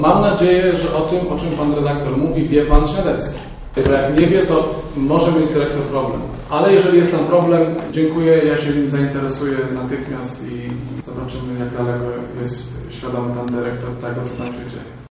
Ten temat poruszył nasz reporter na wczorajszej konferencji prasowej burmistrza Żnina.